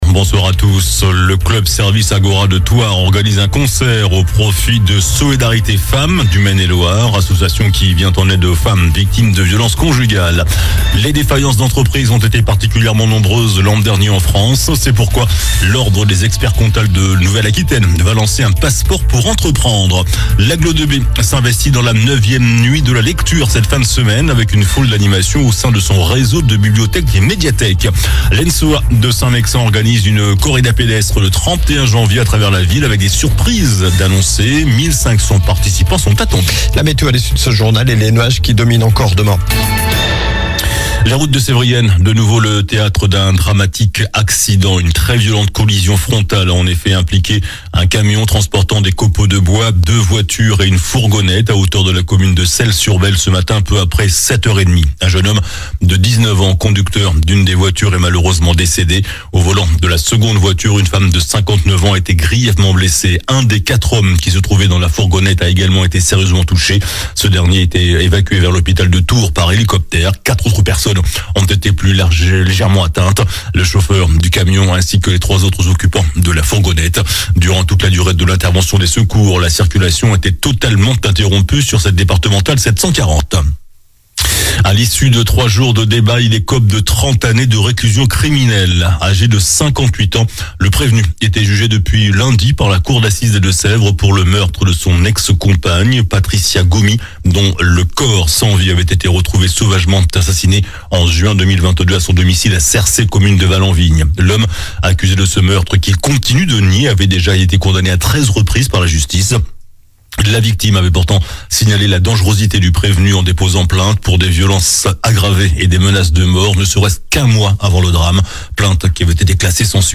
JOURNAL DU MERCREDI 22 JANVIER ( SOIR )